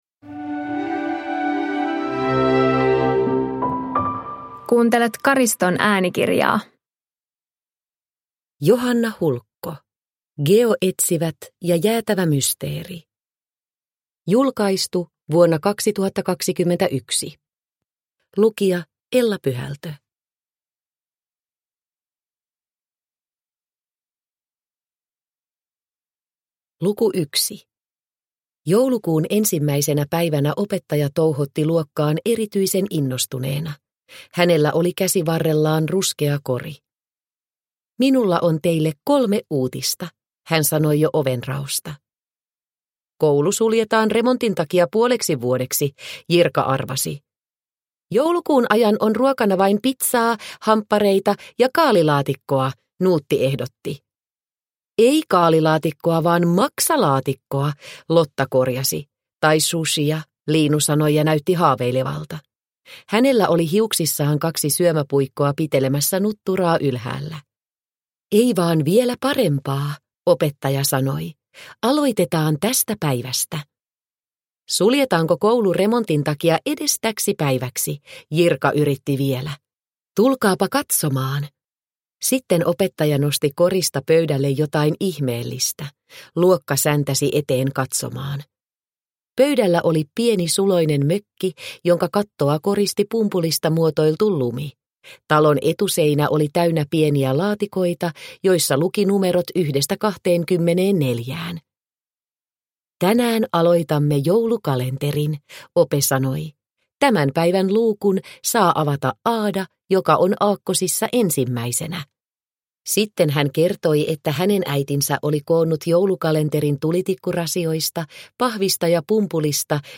Geoetsivät ja jäätävä mysteeri – Ljudbok – Laddas ner